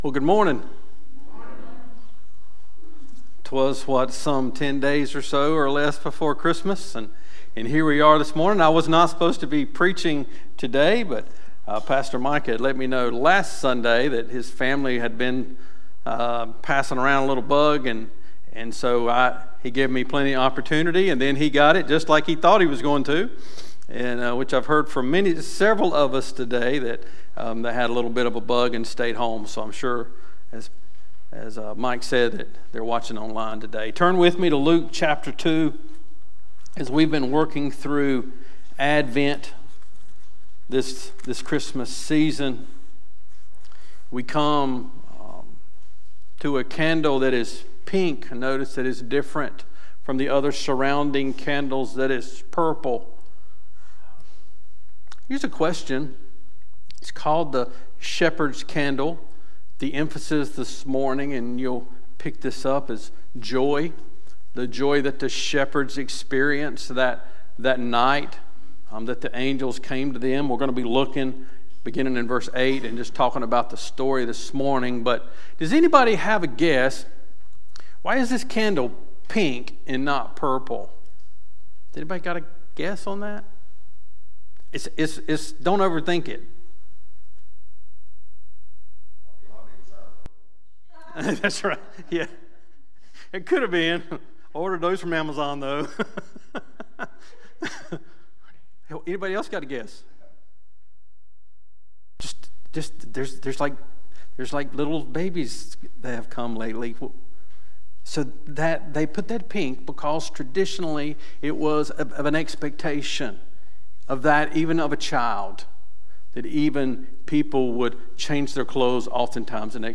Sermon looking through the third candle, the shepherd's candle, of traditional Advent